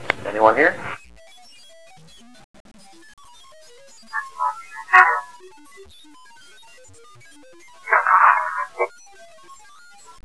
"attitude" whisper......"Who wants to know?"
This is followed by another bit of silence....then a firm,